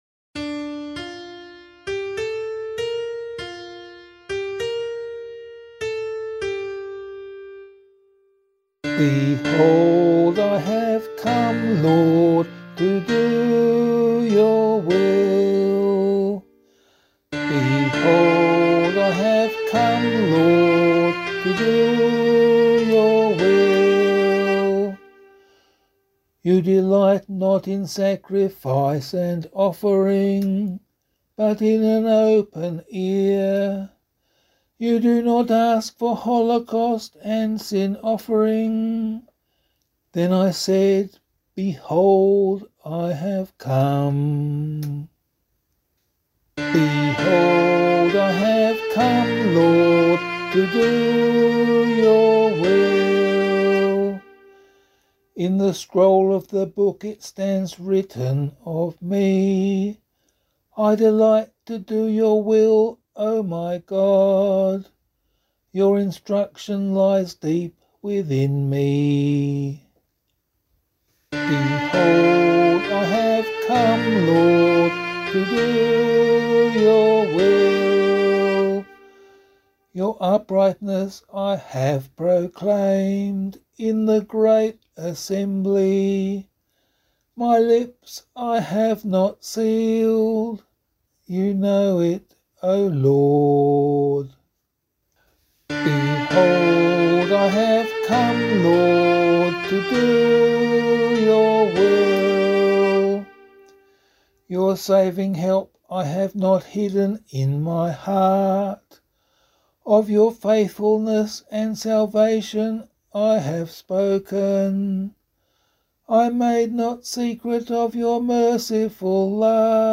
184 Annunciation Psalm [APC - LiturgyShare + Meinrad 4] - vocal.mp3